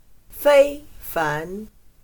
非凡/Fēifán/extraordinario